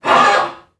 NPC_Creatures_Vocalisations_Robothead [11].wav